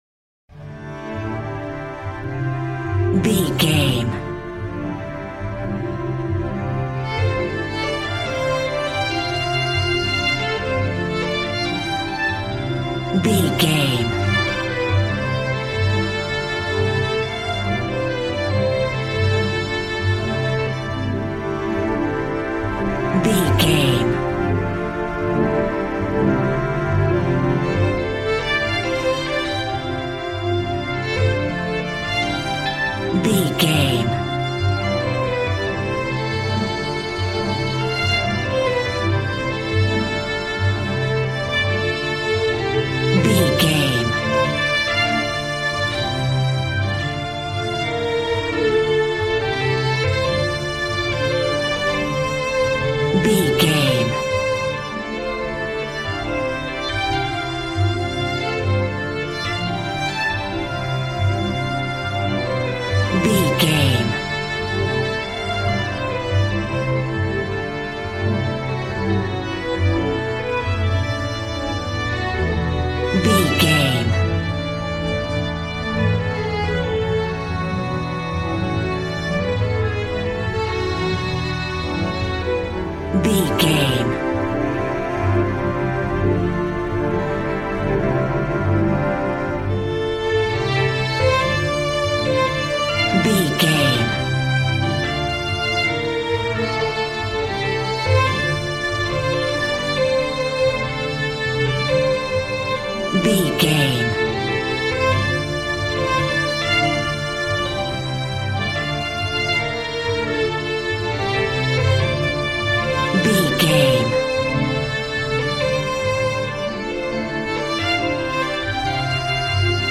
Aeolian/Minor
joyful
conga
80s